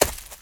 STEPS Leaves, Run 01.wav